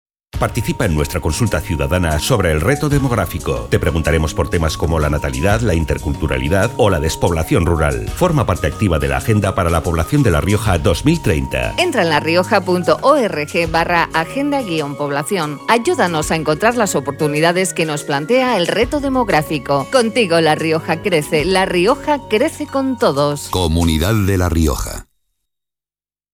Elementos de campaña Cuñas radiofónicas Cuña genérica.